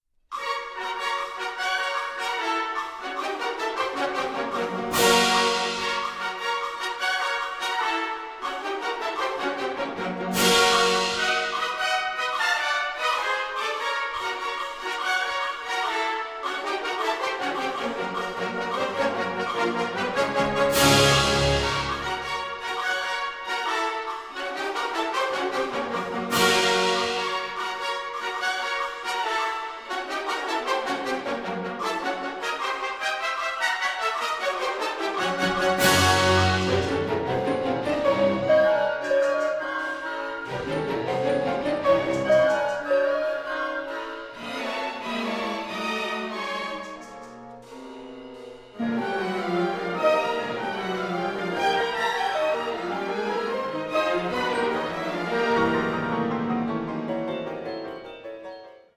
piano
ondes martenot
Recorded in the Grieghallen, Bergen, 20-24 June 2011